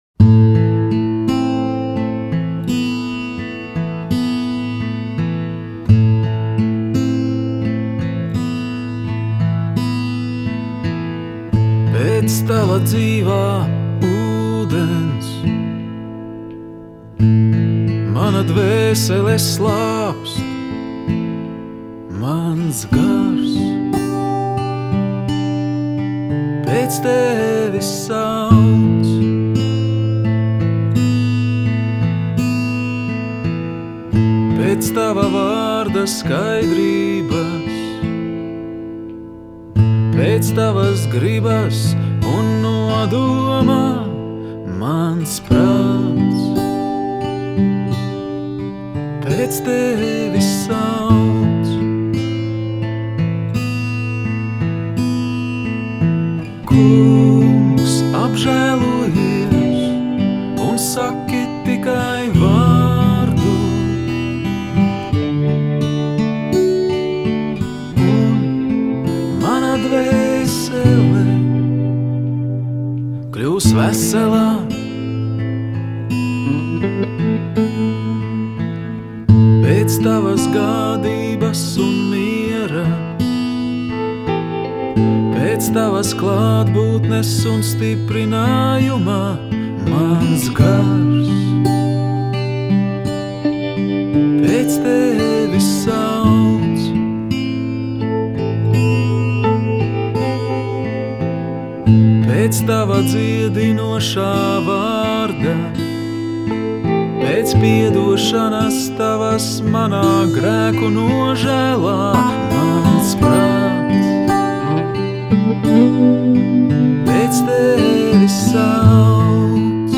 pielūgsmes kompozīcijas